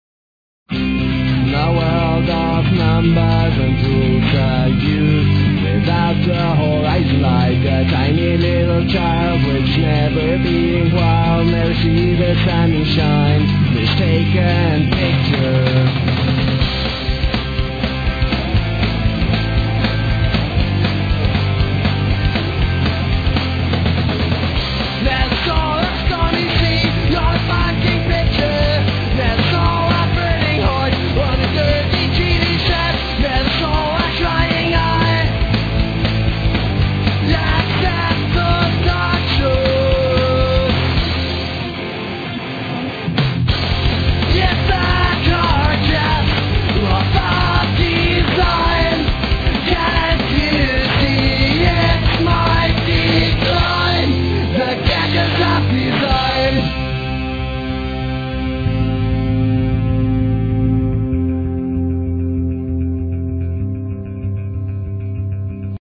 Gitarre, Bass, Schlagzeug.
Und lautes Geschrei.
Schnell, mitreißend, kultverdächtig.